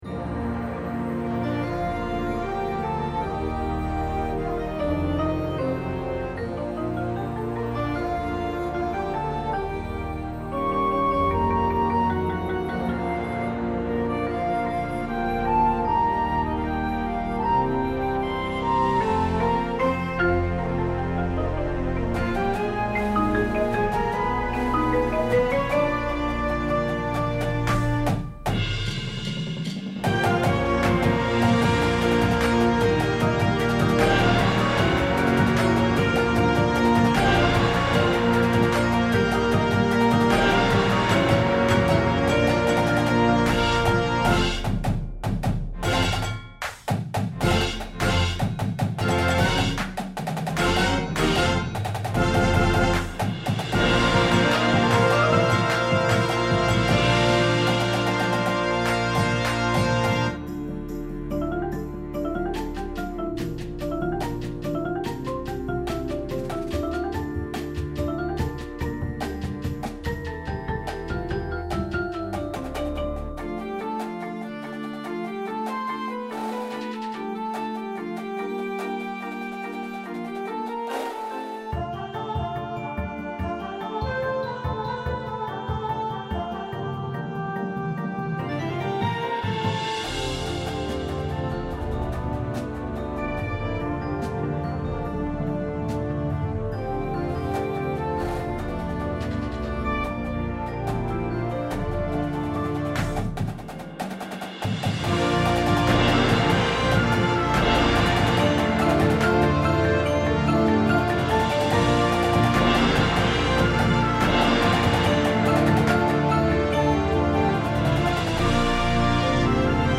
• Flute
• Clarinet 1, 2
• Alto Sax 1, 2
• Trumpet 1, 2
• Horn in F
• Tuba
• Snare Drum
• Synthesizer
• Marimba – Two parts
• Vibraphone – Two parts
• Glockenspiel